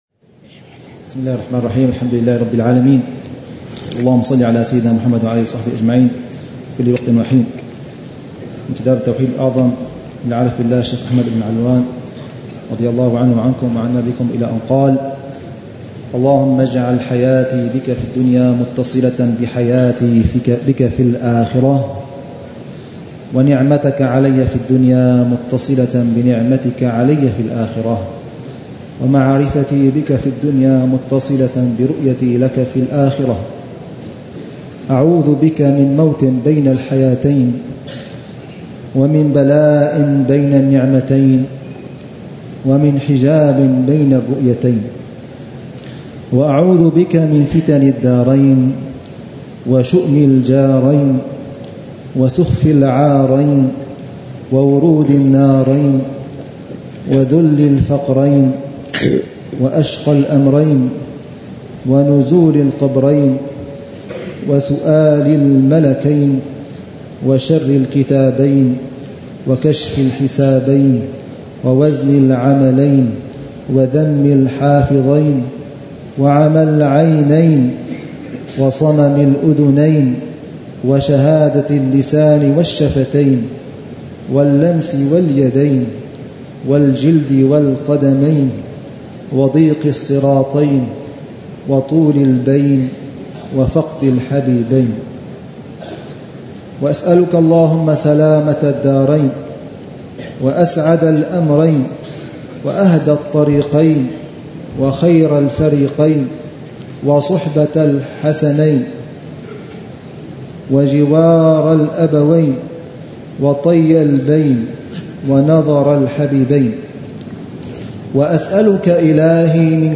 شرح الحبيب عمر بن حفيظ على كتاب: التوحيد الأعظم المبلغ من لا يعلم إلى رتبة من يعلم، للإمام الشيخ أحمد بن علوان، ضمن دروس الدورة الثالثة والعشر